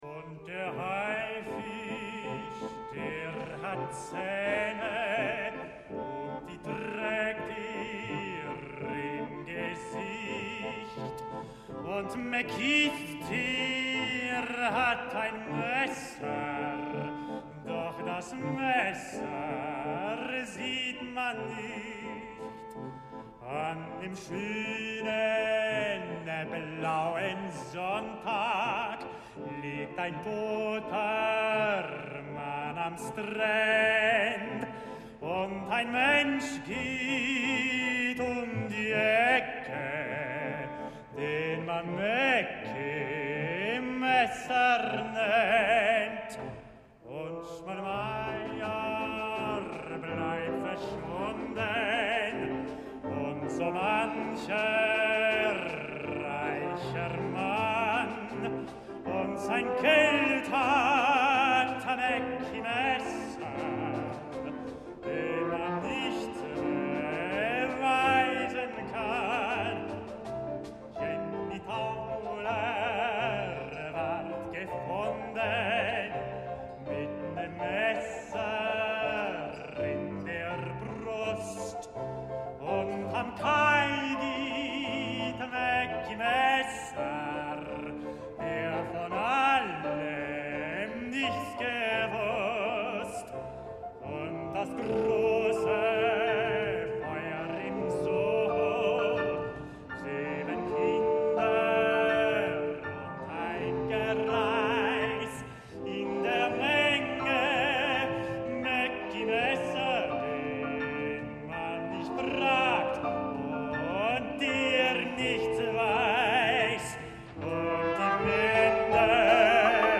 Großer Saal, Wiener Konzerthauses